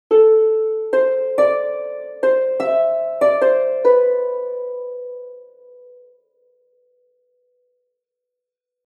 Harp 1.mp3